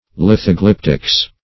lithoglyptics \lith`o*glyp"tics\ (l[i^]th`[-o]*gl[i^]p"t[i^]ks), n.